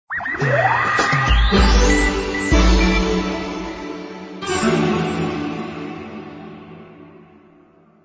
Section#2-Jingles, music logos
All tracks encoded in mp3 audio lo-fi quality.